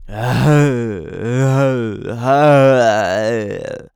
Male_High_Roar_02.wav